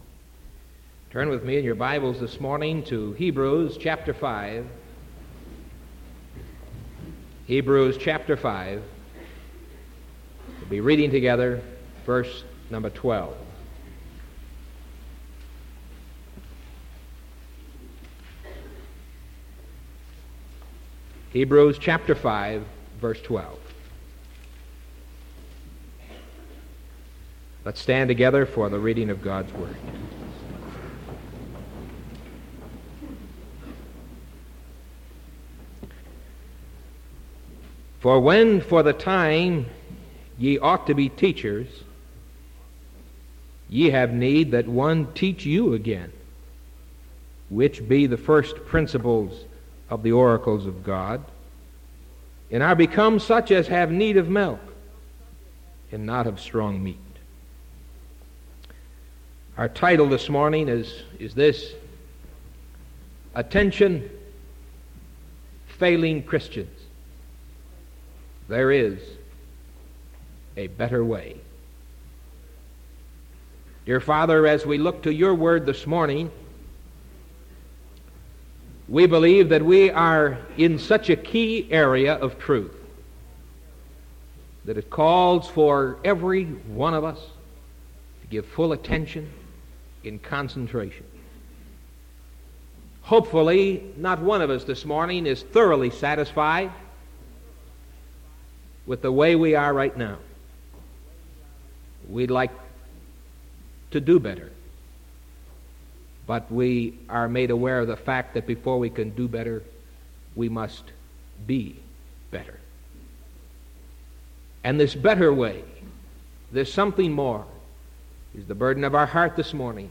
Sermon from September 15th AM